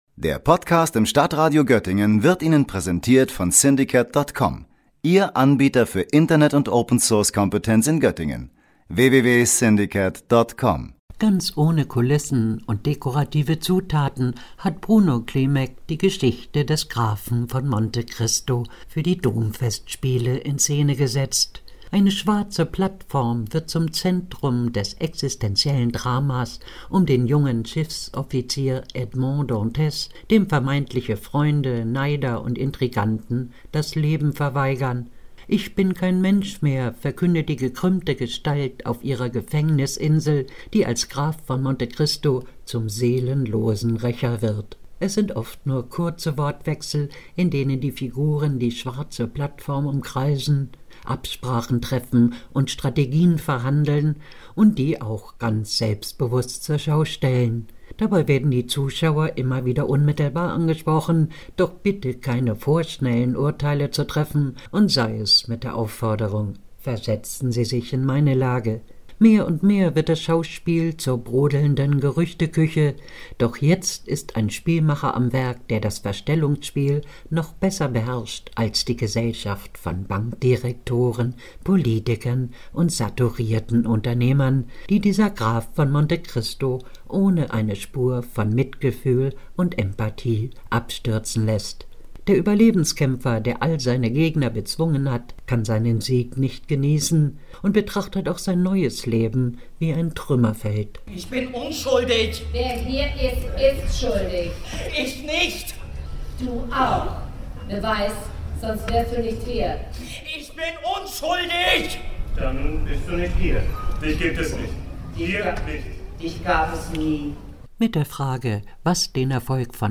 O-Ton 1, Einspieler, „Der Graf von Monte Christo, 16 Sekunden
O-Ton 2, Einspieler, „My Fair Lady”, 25 Sekunden